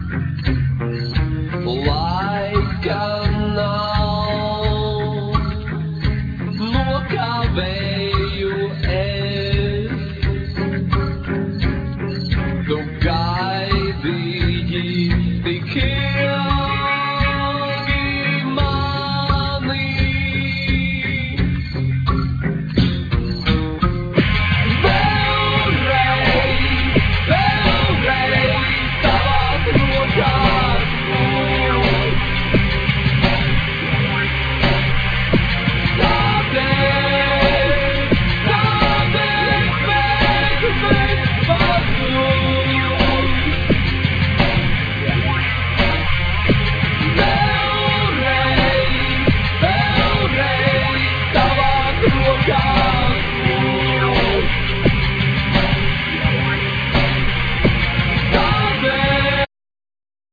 Vocals,Guitar
Bass,Guitar
Drums